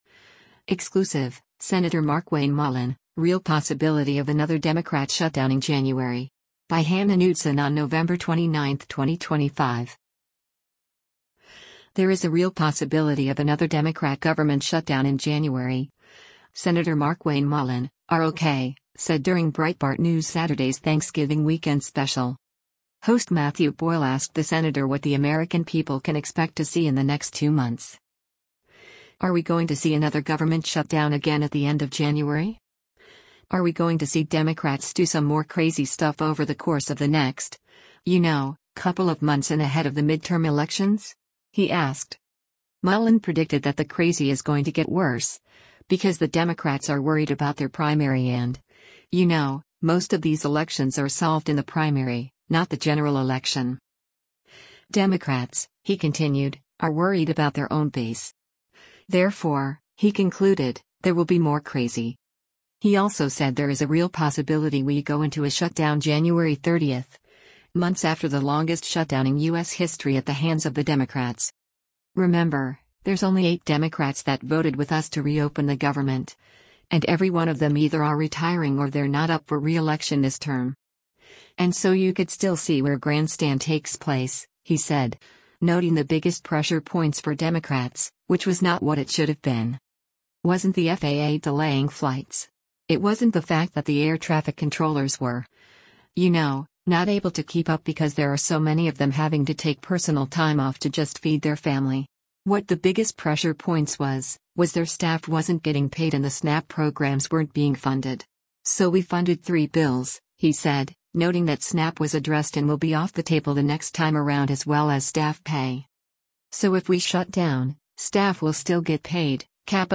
There is a “real possibility” of another Democrat government shutdown in January, Sen. Markwayne Mullin (R-OK) said during Breitbart News Saturday’s Thanksgiving weekend special.